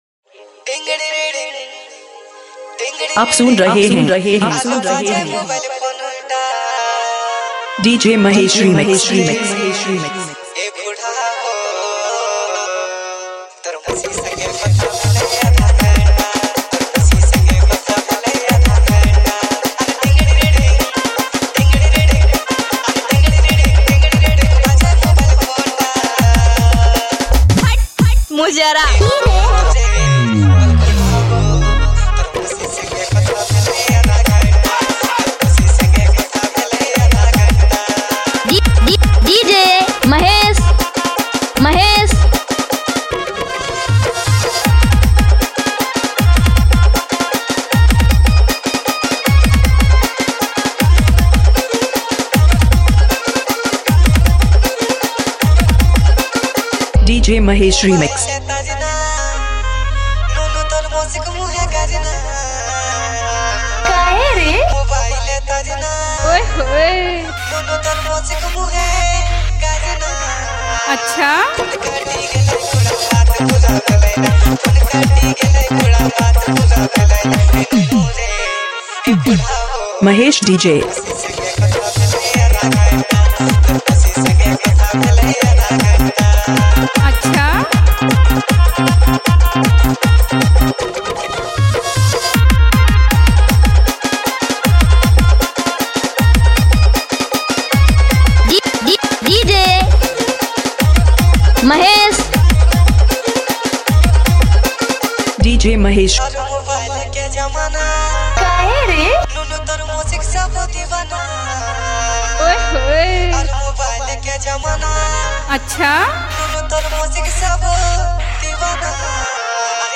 nagpuri Dj mashup